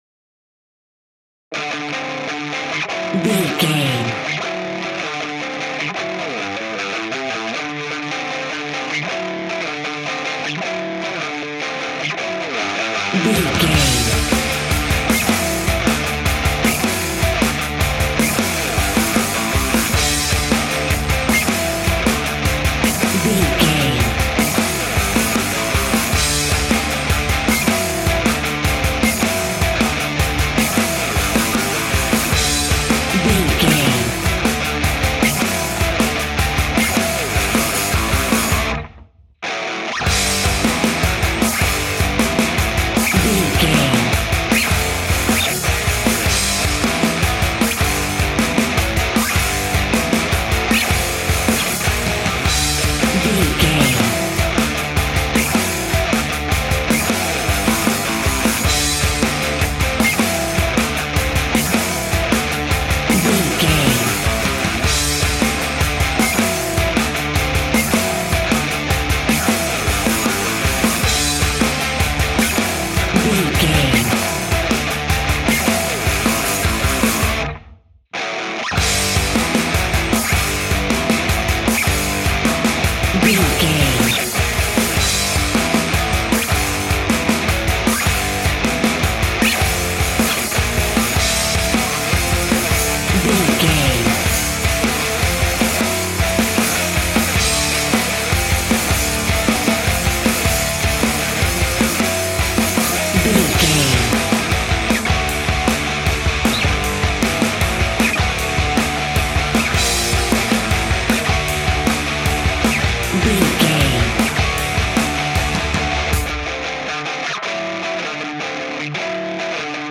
Uplifting
Ionian/Major
D
Fast
hard rock
blues rock
distortion
instrumentals
rock guitars
Rock Bass
heavy drums
distorted guitars
hammond organ